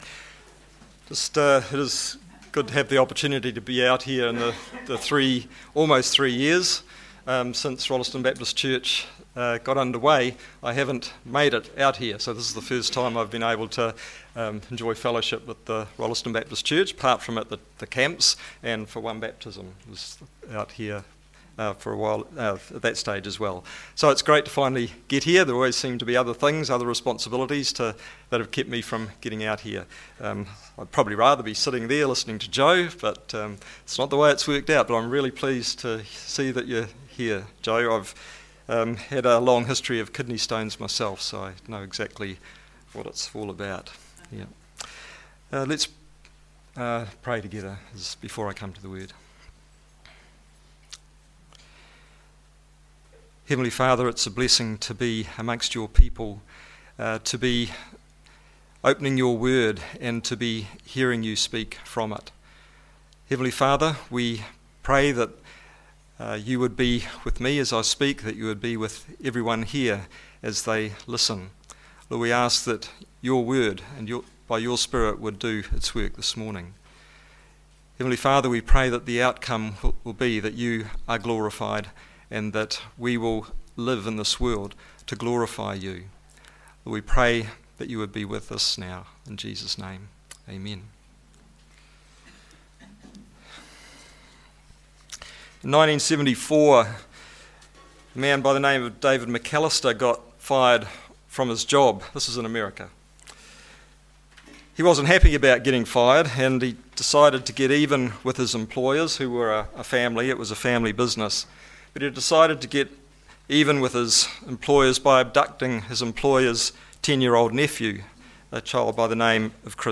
From Series: “Standalone Sermons“